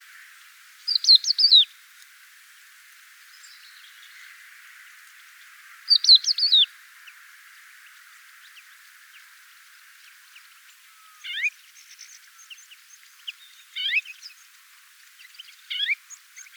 GALERIDA CRISTATA - CRESTED LARK - CAPPELLACCIA